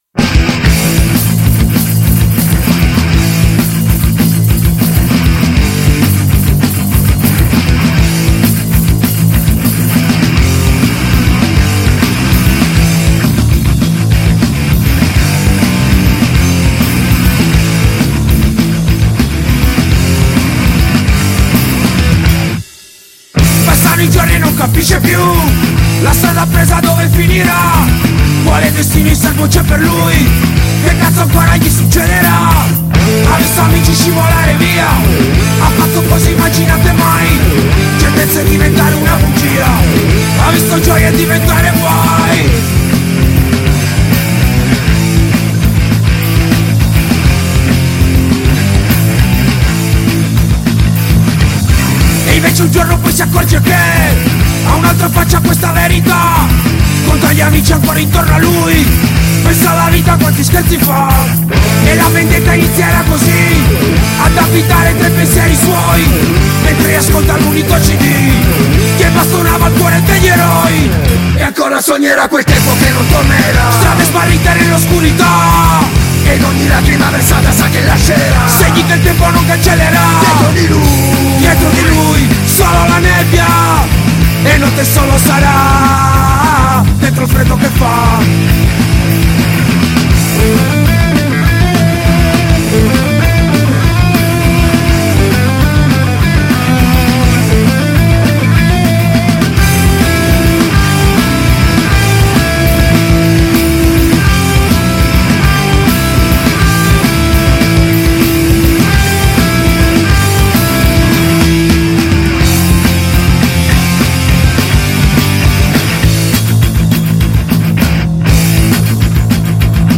Intervista ai Bull Brigade | 20-2-23 | Radio Città Aperta